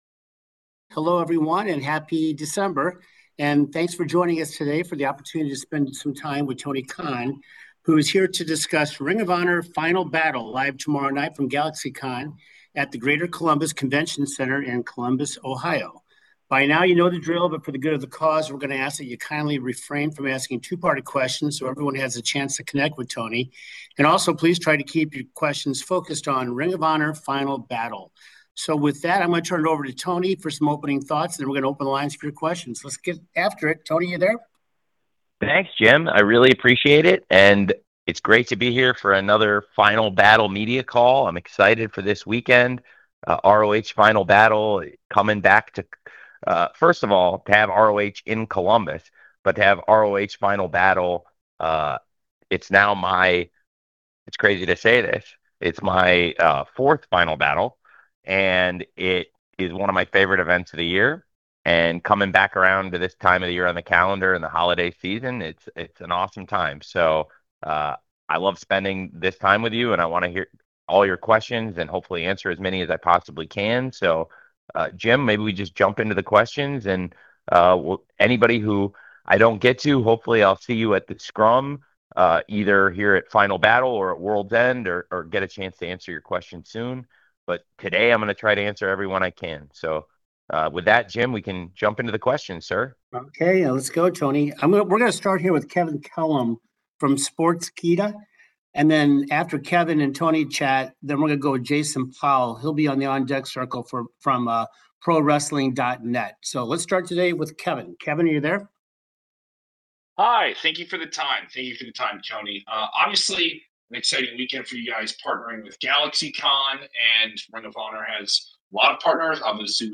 A recap of the Tony Khan Media Call for ROH Final Battle at GalaxyCon in Columbus, Ohio.
Tony Khan, President, General Manager and Head of Creative for Ring of Honor, spoke to the media on Thursday, December 4, 2025 to discuss Ring of Honor Final Battle 2025.